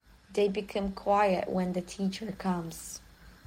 جدول کلمات، جمله ها و معنی آن به همراه تلفظ با سه سرعت مختلف:
تلفظ با سرعت‌های مختلف